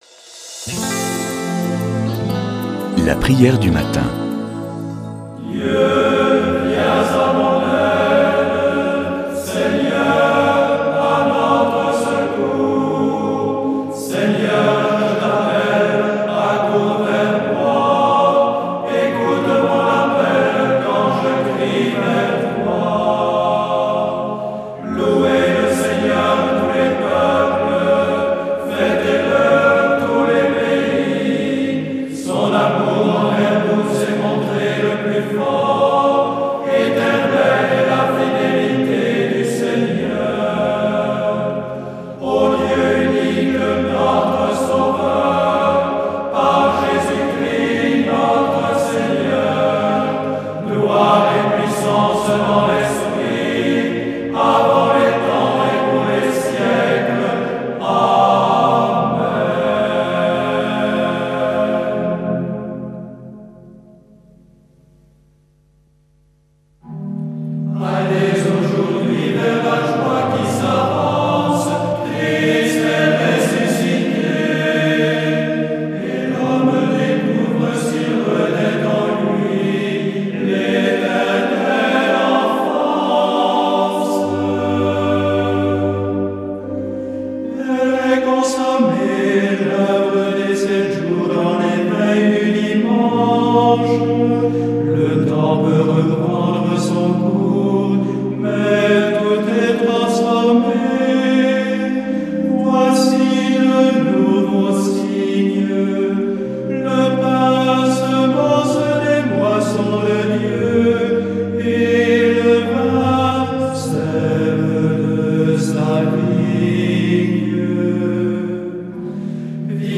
Prière du matin
ABBAYE DE TAMIE